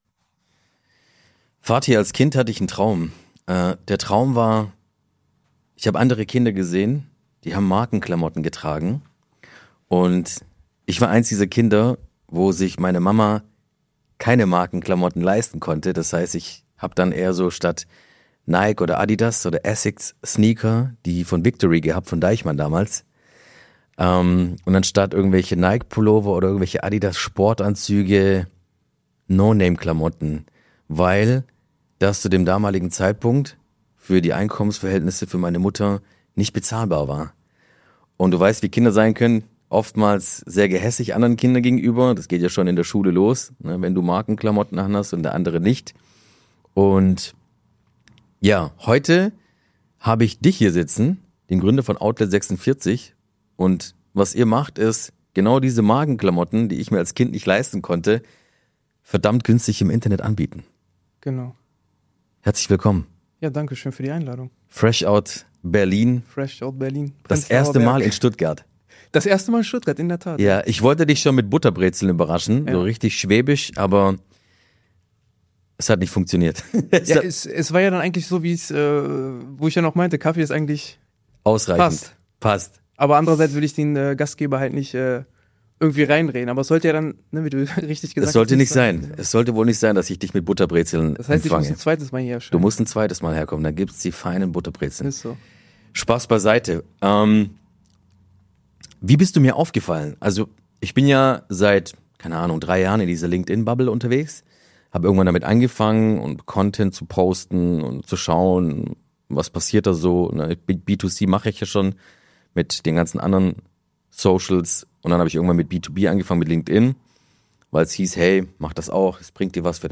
Ein Gespräch über Geld, Widerstand und die Frage, warum manche trotz Gegenwind immer weitergehen.